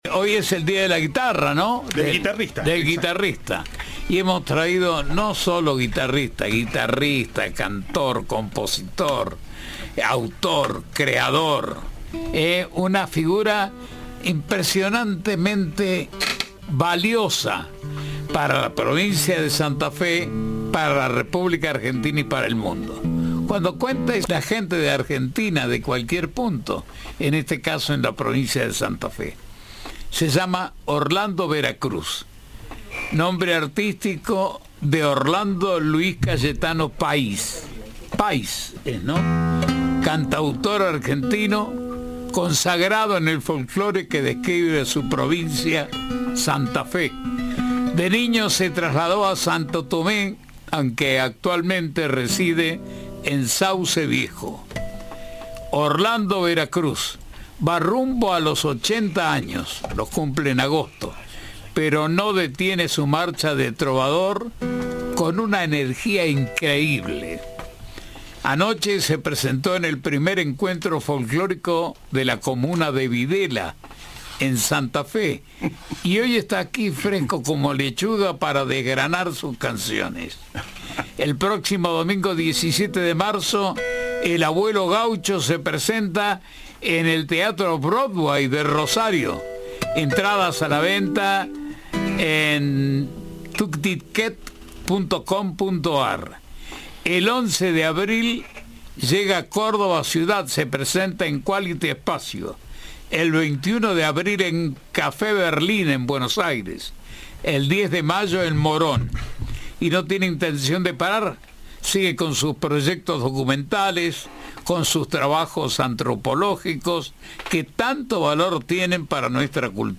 Orlando Vera Cruz llenó de música los estudios de Cadena 3 junto a Rony Vargas
Orlando Luis Cayetano Pais es un cantautor argentino consagrado en el folklore.